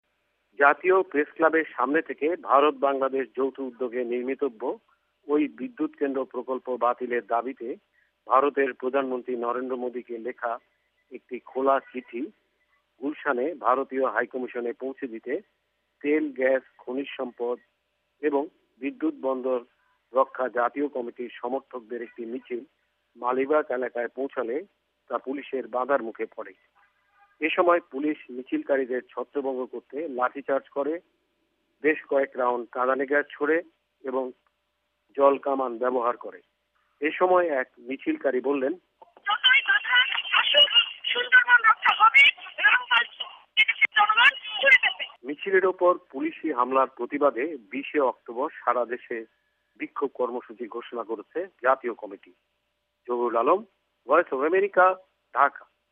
রিপোর্ট (প্রতিবাদ)